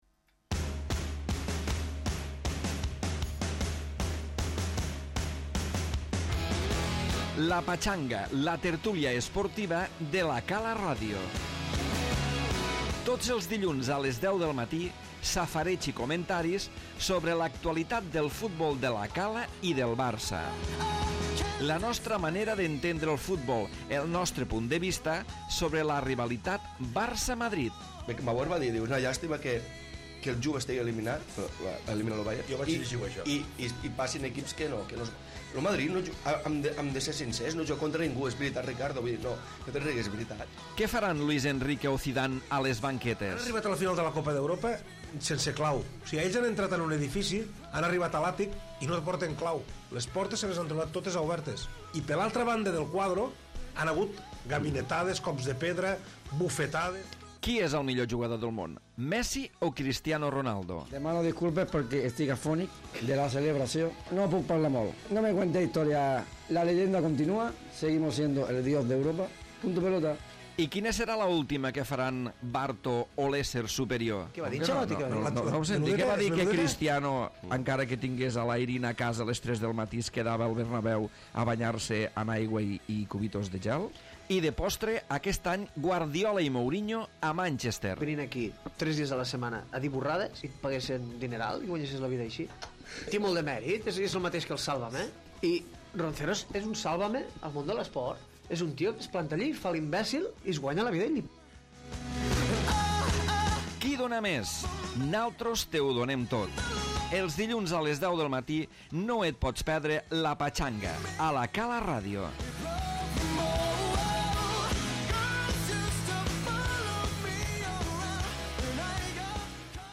Primer programa de l'any de la tertúlia futbolística